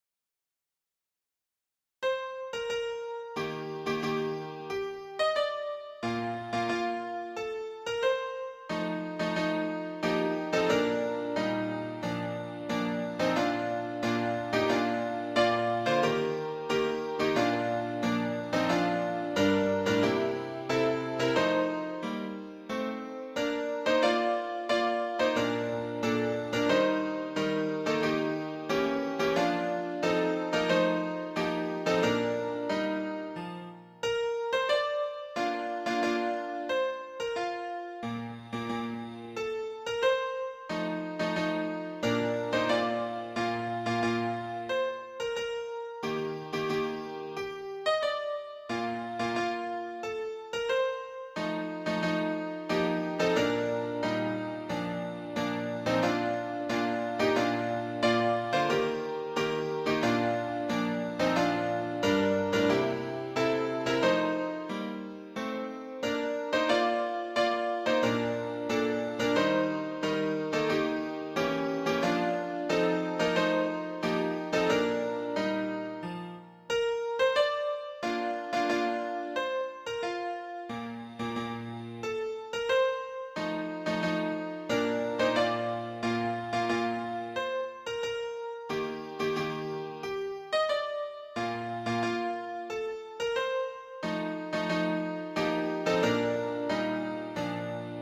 伴奏
示唱